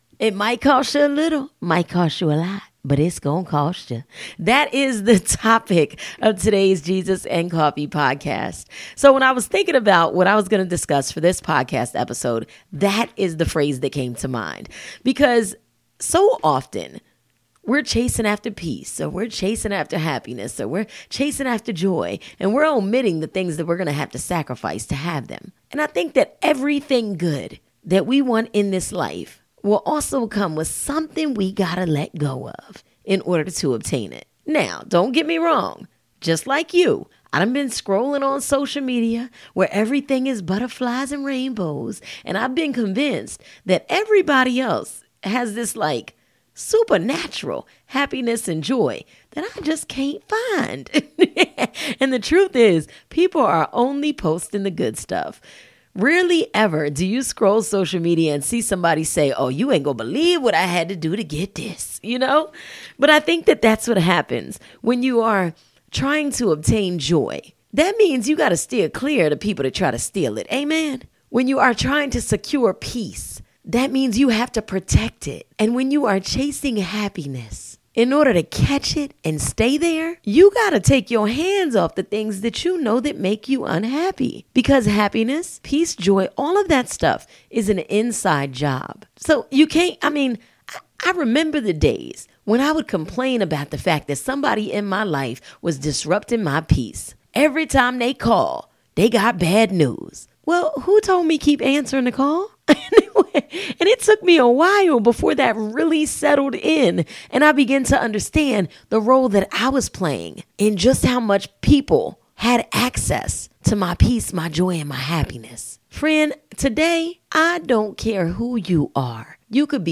Having a conversation with a friend; designed to help sprinkle some upbeat positivity in the listeners day. There will be storytelling, the occasional guest interview, and biblical reference to help set the tone.